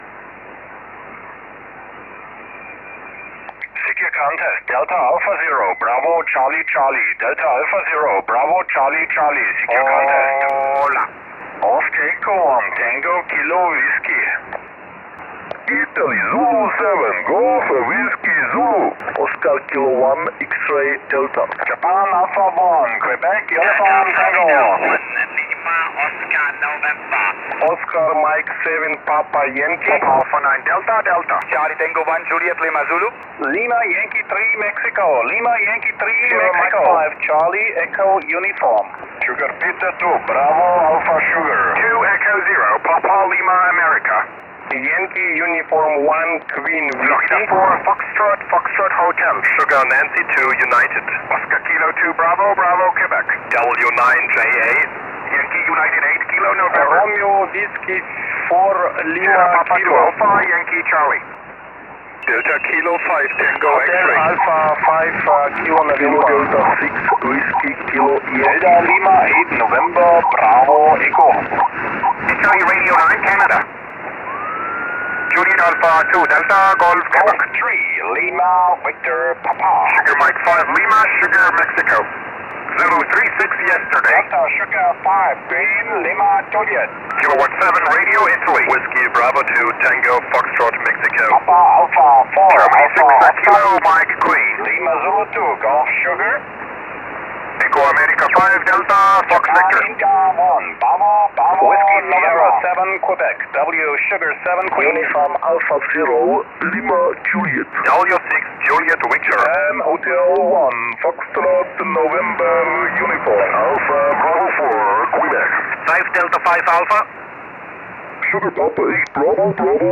Die Aufgabe war erneut, aus einem SSB-Pileup so viele Rufzeichen wie möglich korrekt zu loggen.